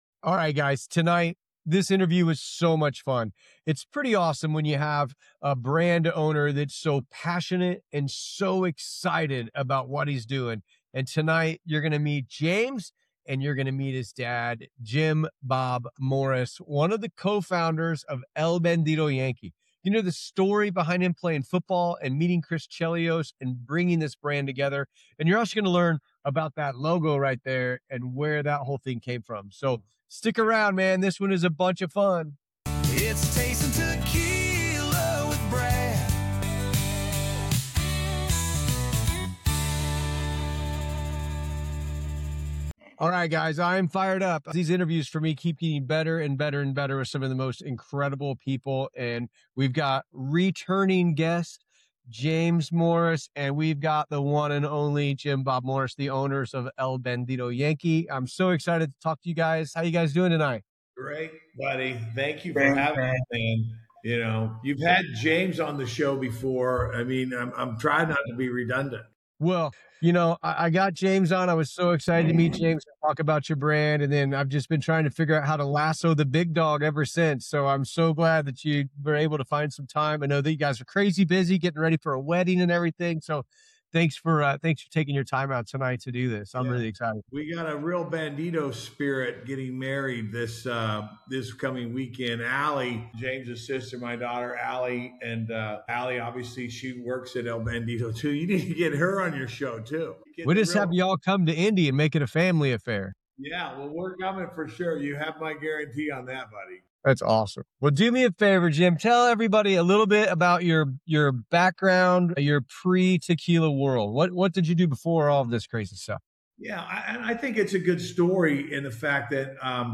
El Bandido Yankee tequila review interview